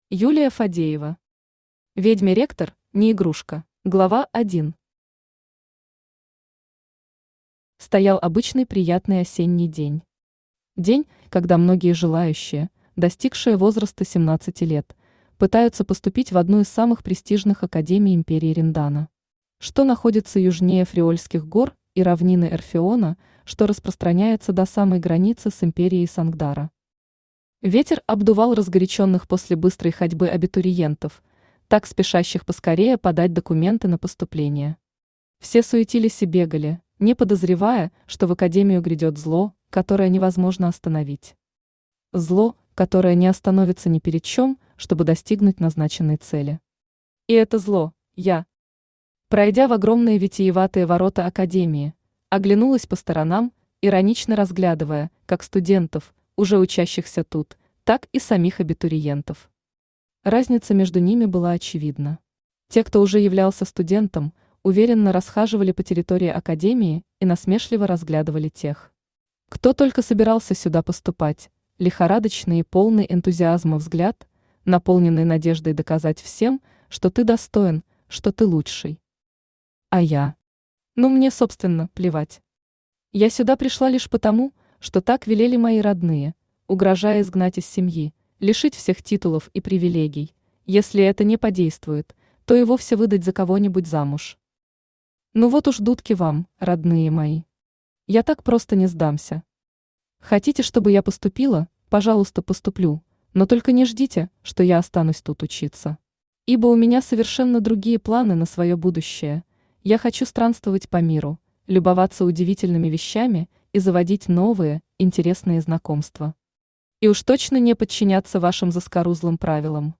Аудиокнига Ведьме ректор – не игрушка | Библиотека аудиокниг
Aудиокнига Ведьме ректор – не игрушка Автор Юлия Александровна Фадеева Читает аудиокнигу Авточтец ЛитРес.